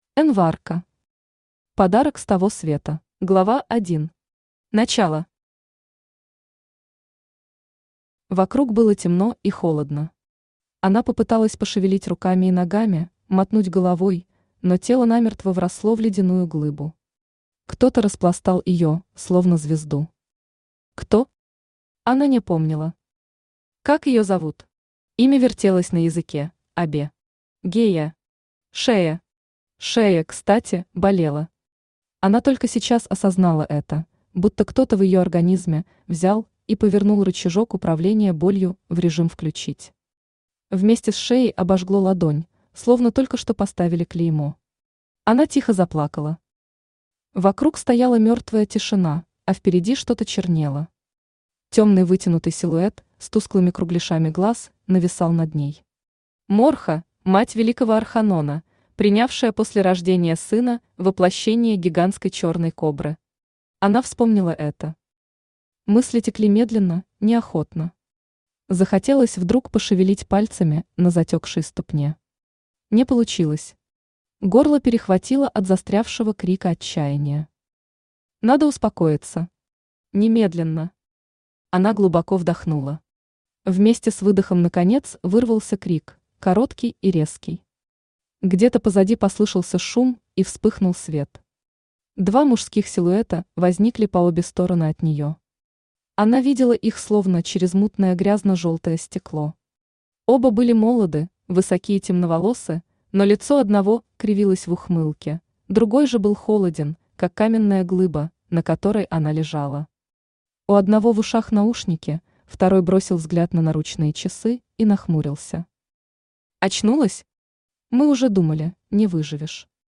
Aудиокнига Подарок с того света Автор Эн Варко Читает аудиокнигу Авточтец ЛитРес. Прослушать и бесплатно скачать фрагмент аудиокниги